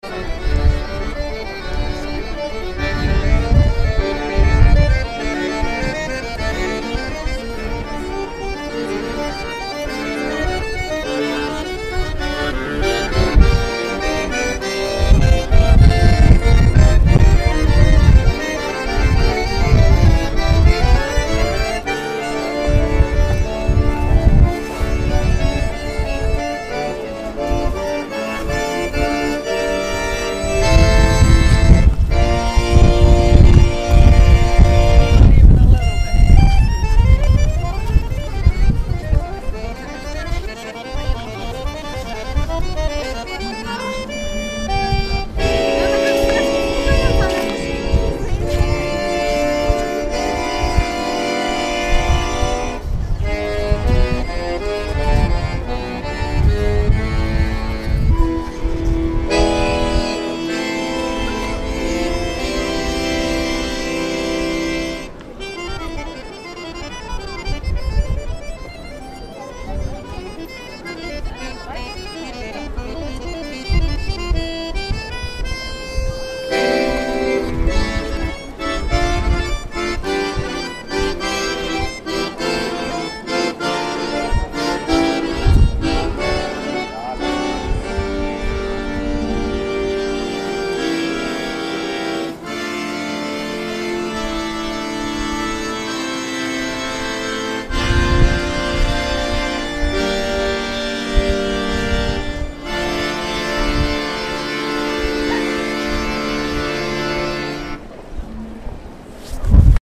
A windy day in Krakow